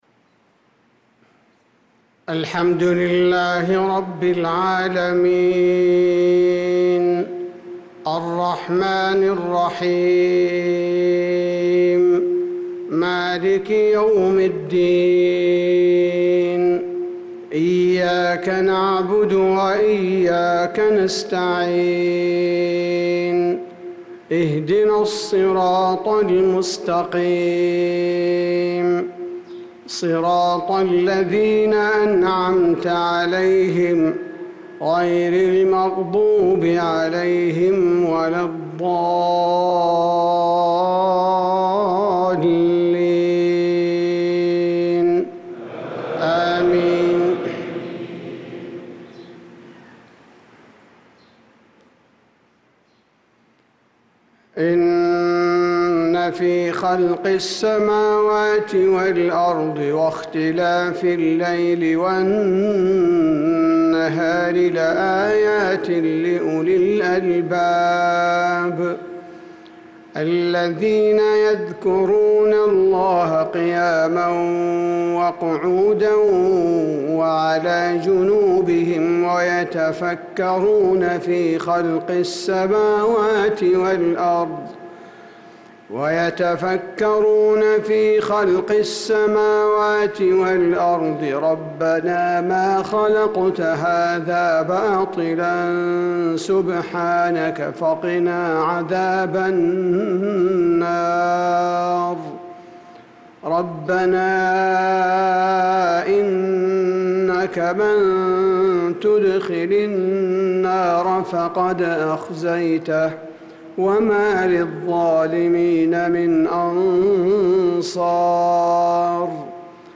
صلاة العشاء للقارئ عبدالباري الثبيتي 12 ذو الحجة 1445 هـ